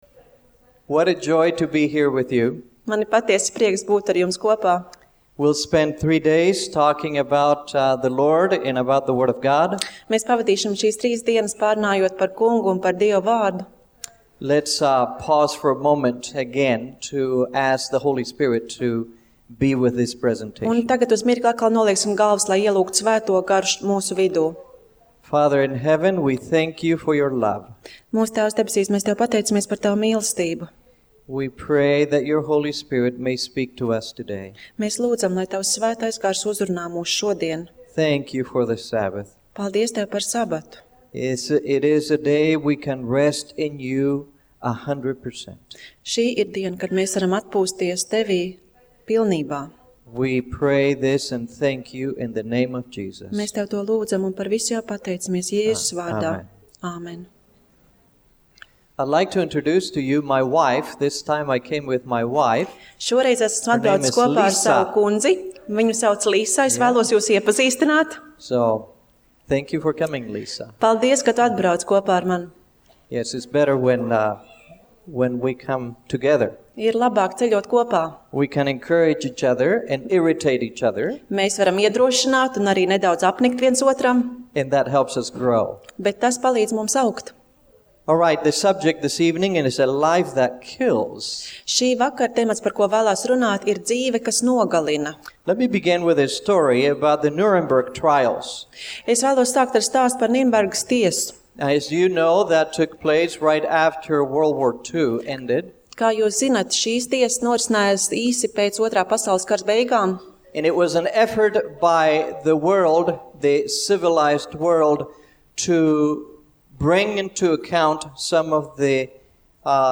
Seminārs - Neatklātais stāsts par nepabeigto darbu